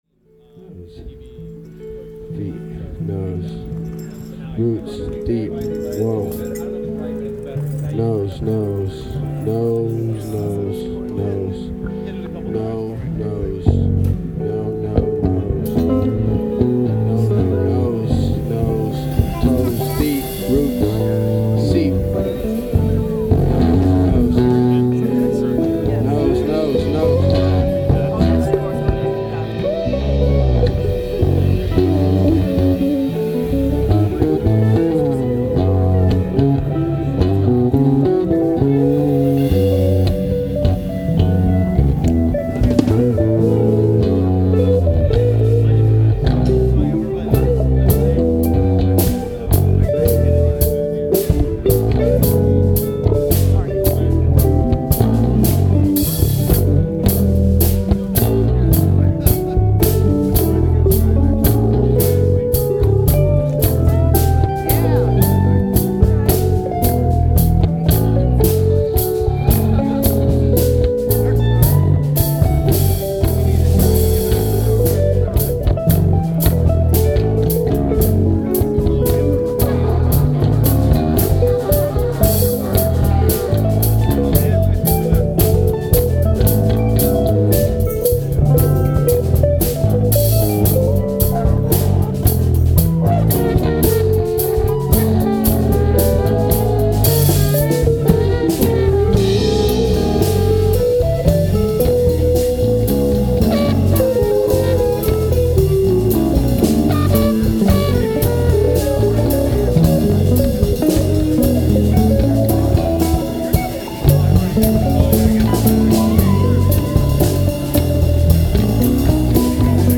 guitar
voice and trumpet
drums
bass
For the second clip, the bass is plennnnnty loud!